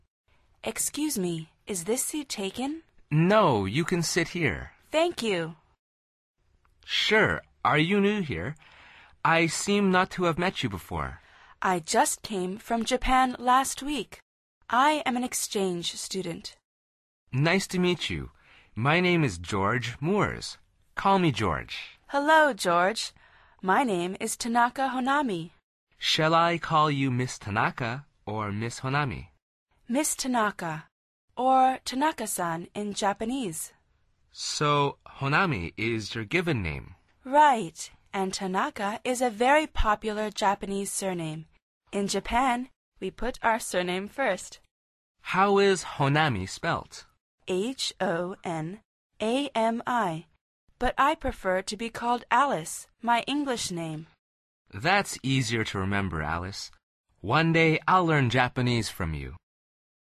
Curso Básico de Conversación en Inglés
Al final repite el diálogo en voz alta tratando de imitar la entonación de los locutores.